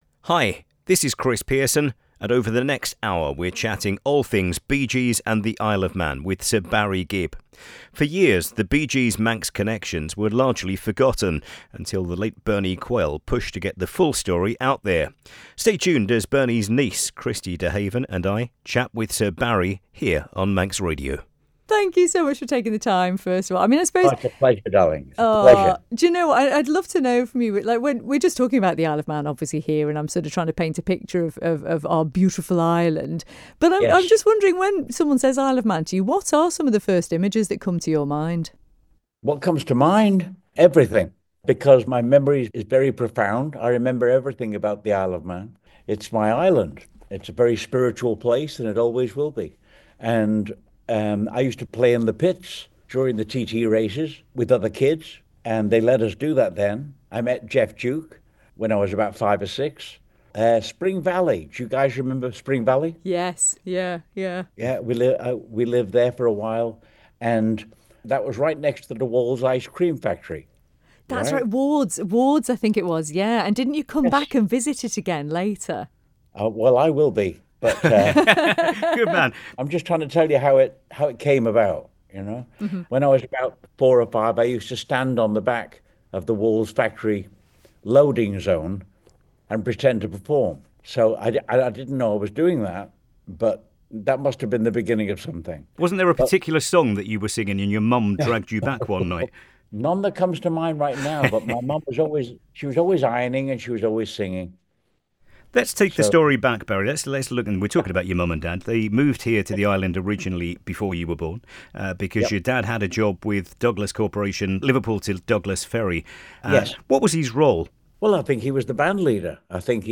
懐かしい音源も交えてバリーが生れ故郷への思いを語るマン島ラジオ独占インタビュー（１時間）のリンクが公開されました。
もちろん生放送ではなく、事前に録音されたマイアミのバリーとの電話インタビューではありましたが、話題が子ども時代に及んだこともあり、独特の”現地”感がありました。
インタビューの最後をしめくくったのはロビンが歌い上げる「 Ellan Vannin 」でした（エラン・ヴァニンとは”マン島”を意味するマン島の言葉です） 時間帯が時間帯だけに聞きのがした方は上のリンクからどうぞ。